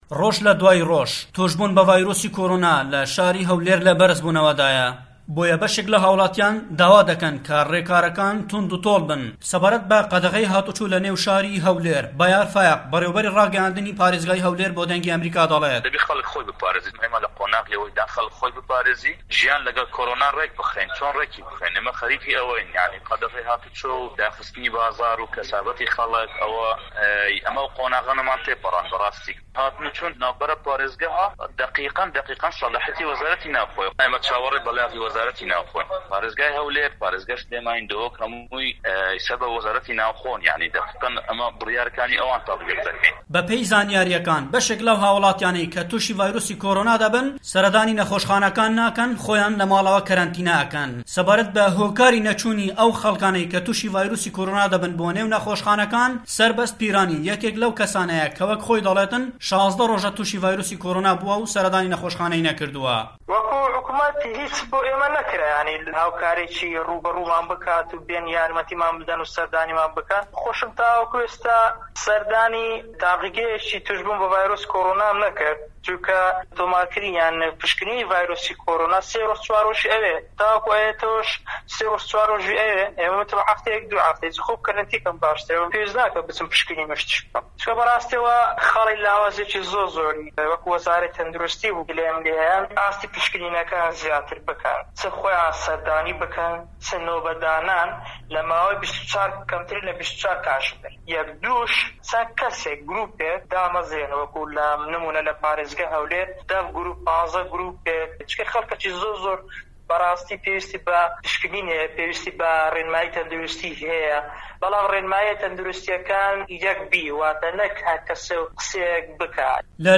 ڕاپـۆرتی